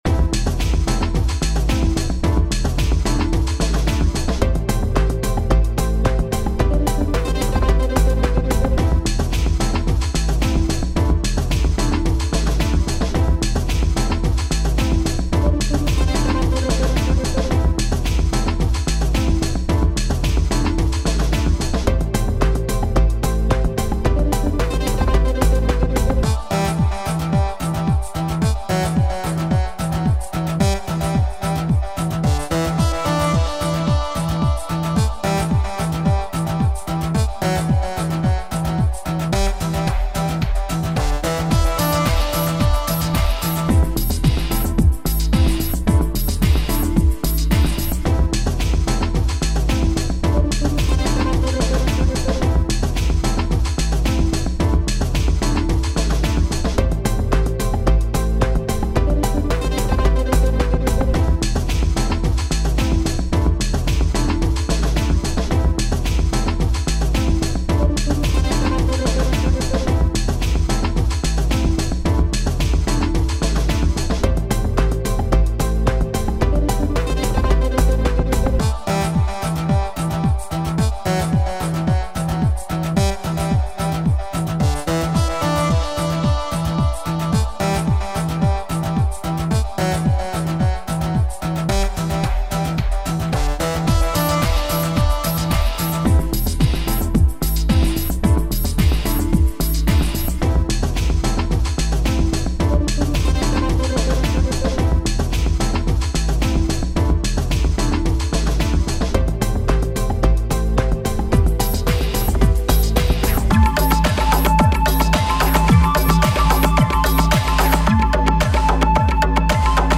Home > Music > Beats > Chasing > Restless > Mysterious